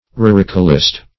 Search Result for " ruricolist" : The Collaborative International Dictionary of English v.0.48: Ruricolist \Ru*ric"o*list\, n. [L. ruricola; rus, ruris, the country + colere to inhabit.]